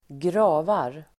Uttal: [²gr'a:var]
gravar.mp3